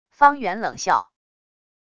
方源冷笑wav音频